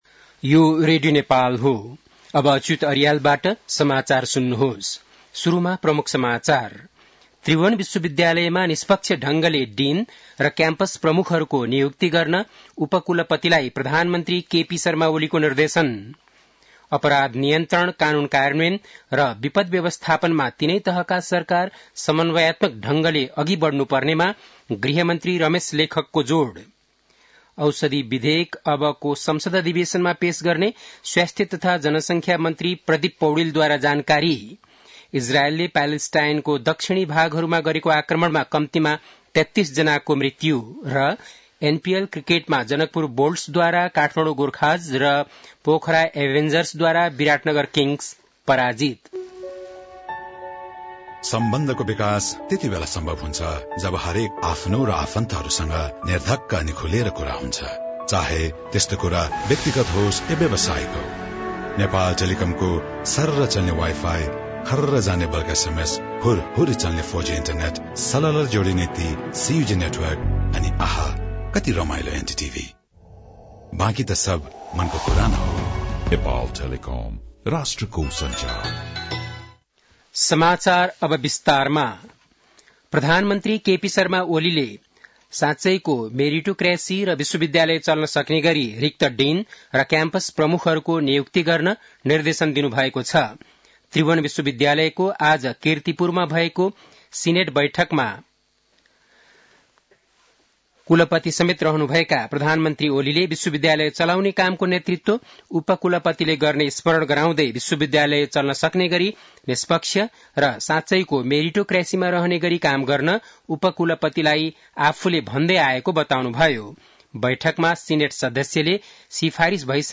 बेलुकी ७ बजेको नेपाली समाचार : २८ मंसिर , २०८१
7-PM-Nepali-NEWS.mp3